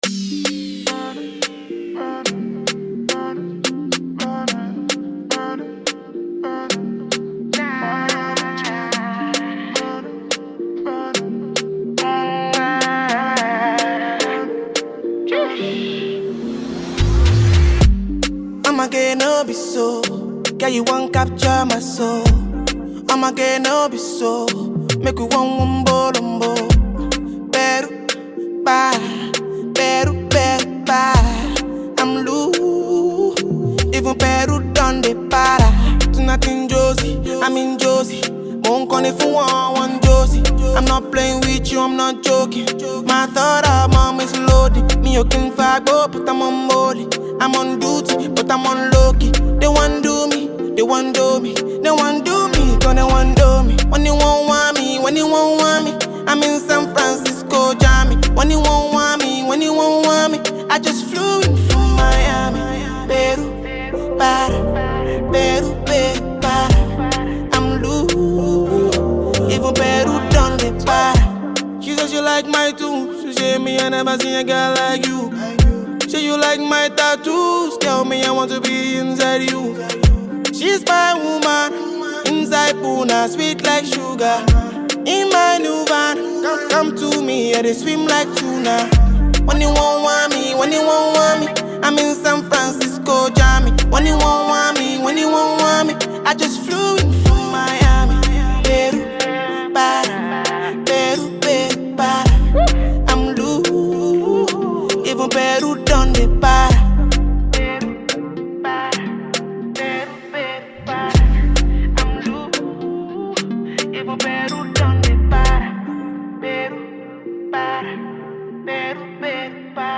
smashing mid-tempo tune